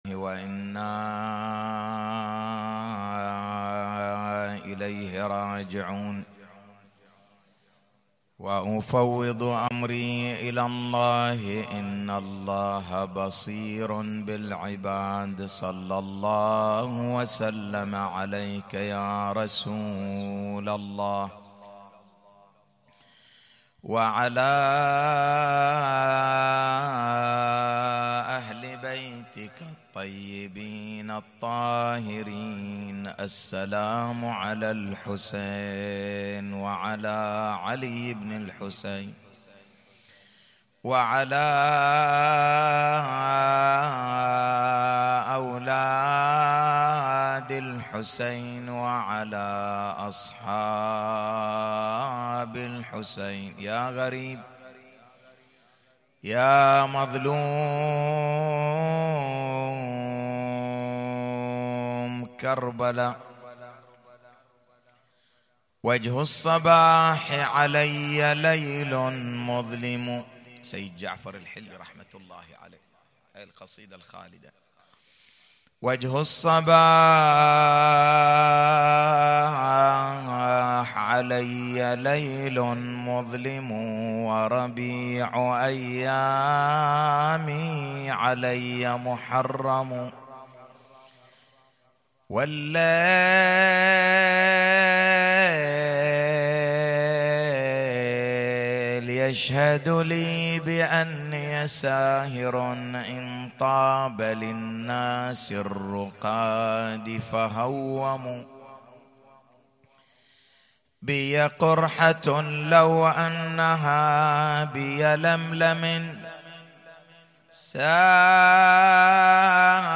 تغطية شاملة: إعادة العشرة الحسينية ليلة 2 محرم 1441هـ